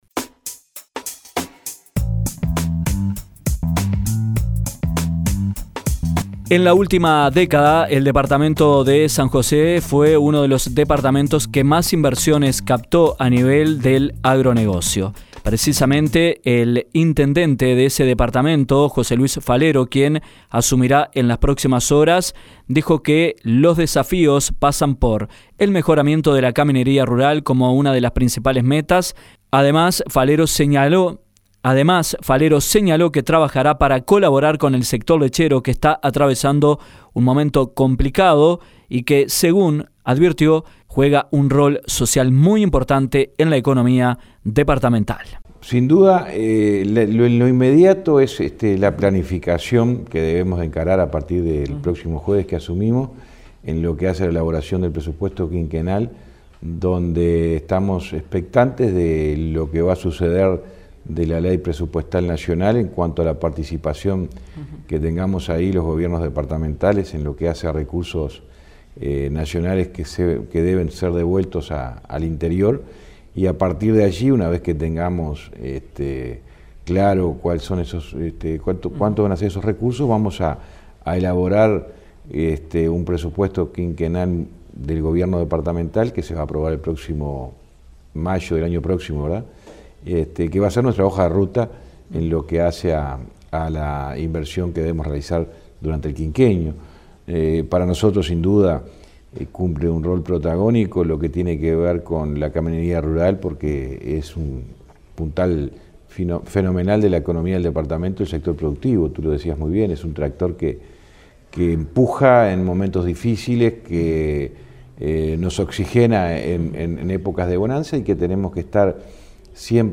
José Luis Falero, intendente de San José, dialogó con Dinámica Rural sobre los objetivos del gobierno departamental para el próximo quinquenio. El próximo titular de la comuna maragata marcó el mejoramiento de la caminería rural como una de las principales metas.